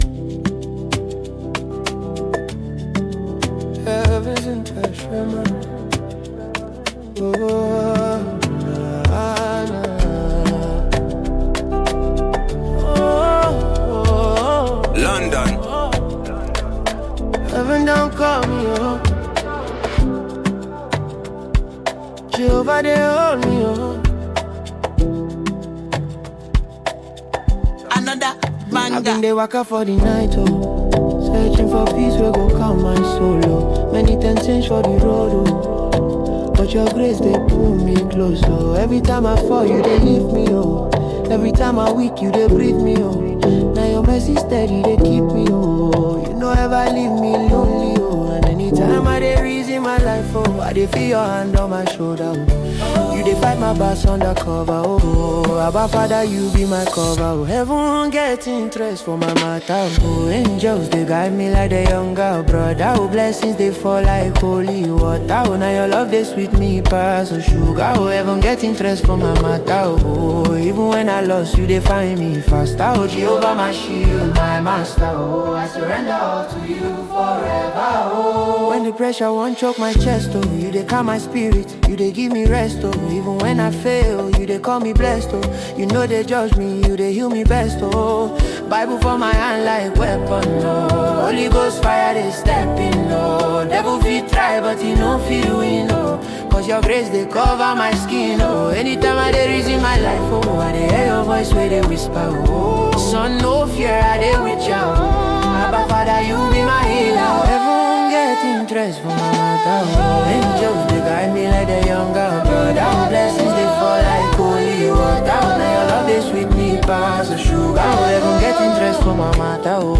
deeply emotional and introspective song